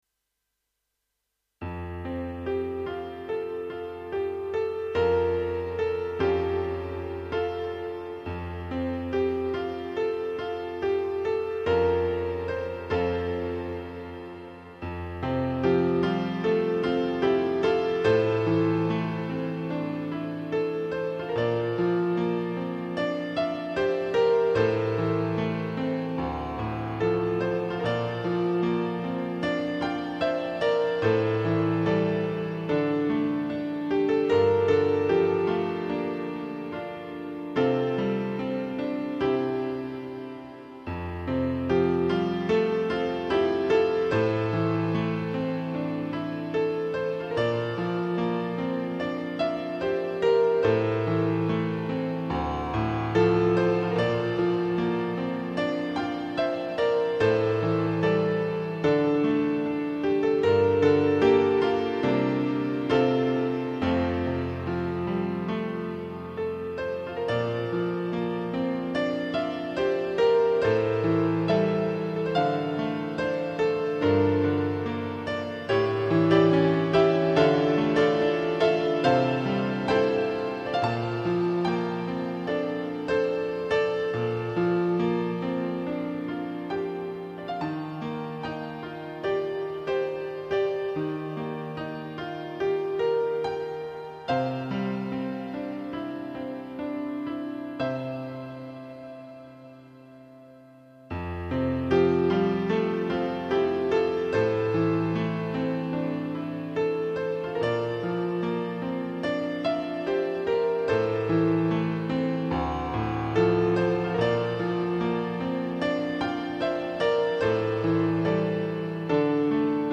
弾きにくいところは微妙に和音の音を削りましたが、ほぼ原曲を再現しています。